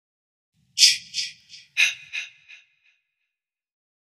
Friday The 13th Chchch Ahahah Sound Effect Free Download